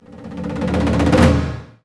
A#3 MDDRU01R.wav